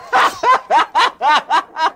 Risada Seu Madruga
Risada do Seu Madruga (Ramón Valdés) do seriado Chaves.
risada-seu-madruga.mp3